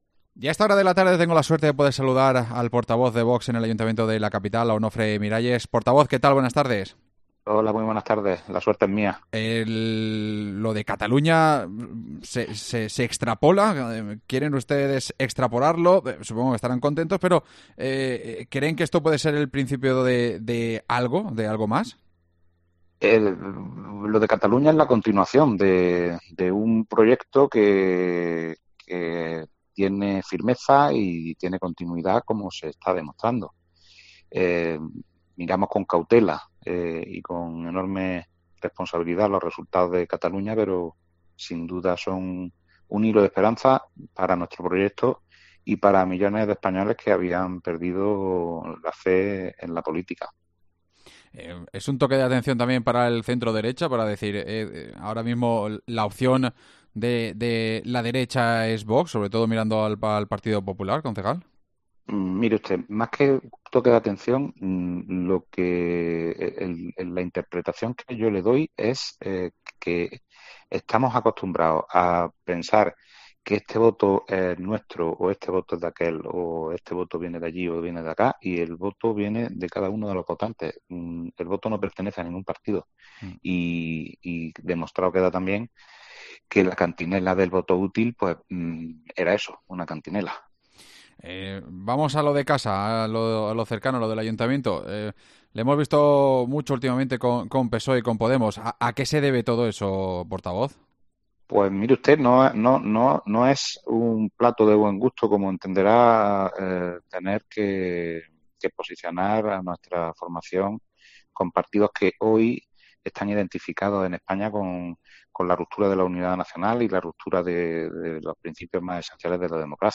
AUDIO: Entrevistamos al portavoz municipal de VOX en Granada